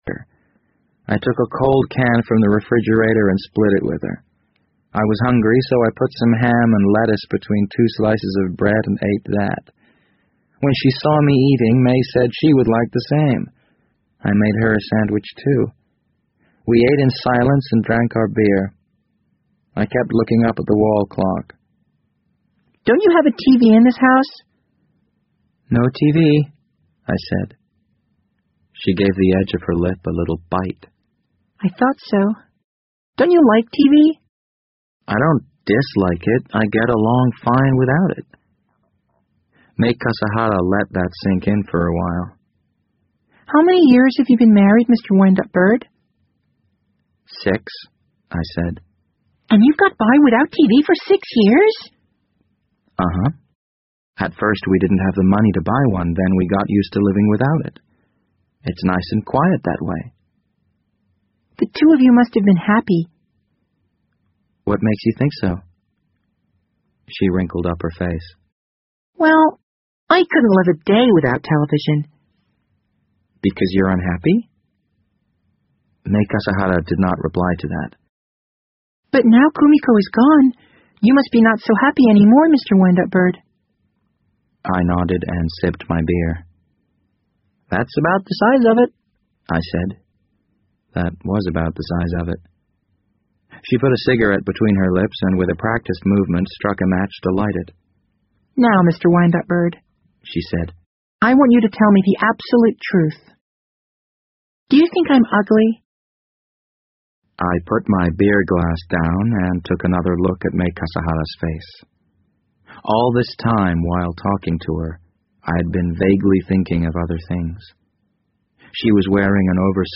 BBC英文广播剧在线听 The Wind Up Bird 005 - 16 听力文件下载—在线英语听力室